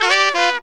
HORN RIFF 10.wav